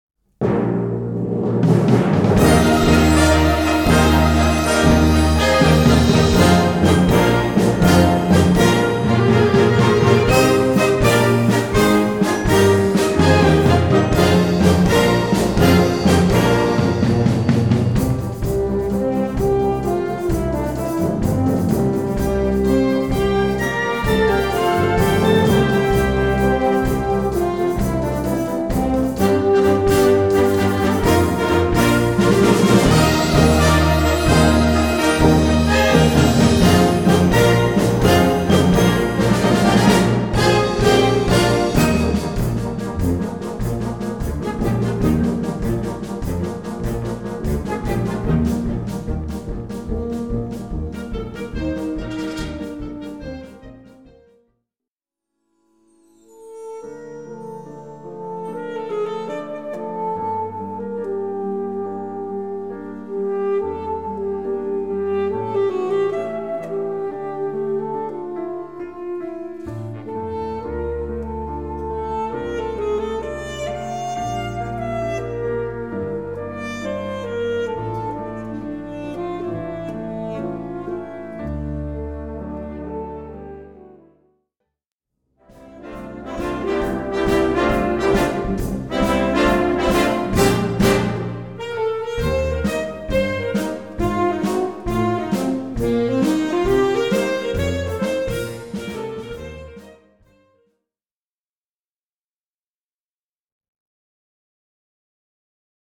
Catégorie Harmonie/Fanfare/Brass-band
Sous-catégorie modernes populaires musique
Instrumentation Ha (orchestre d'harmonie)